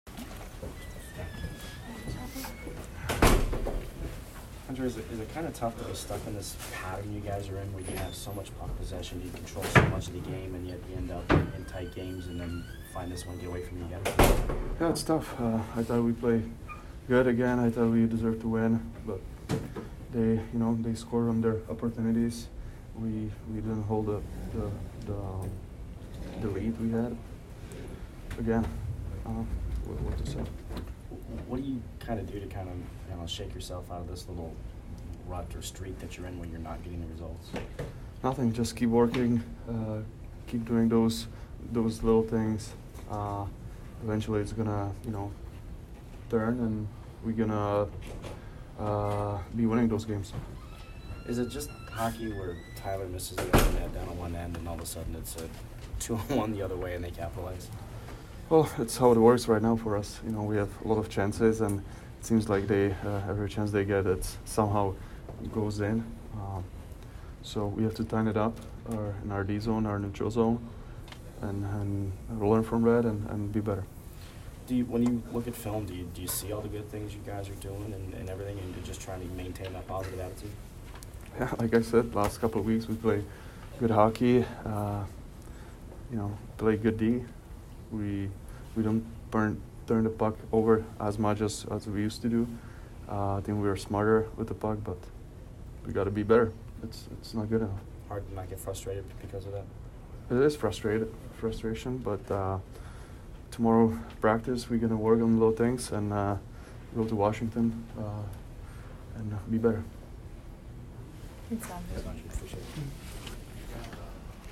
Palat post-game 12/19